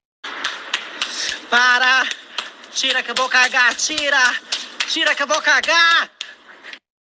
Categoria: Sons virais